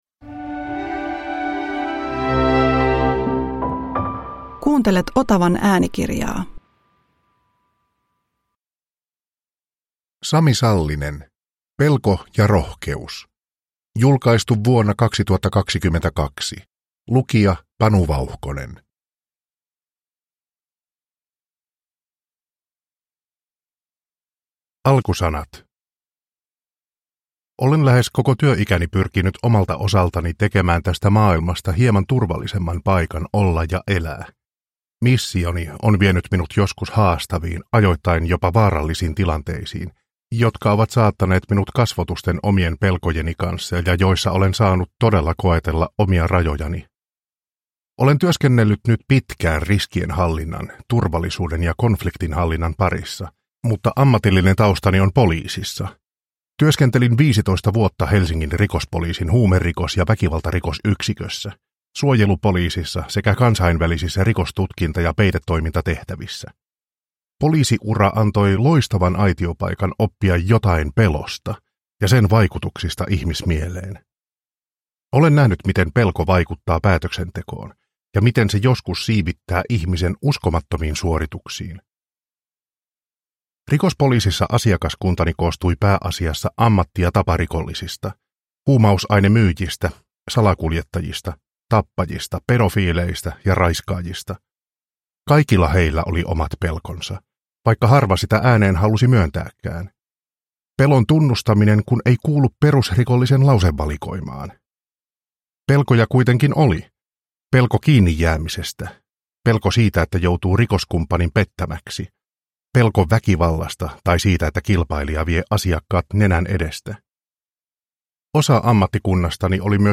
Pelko ja rohkeus – Ljudbok – Laddas ner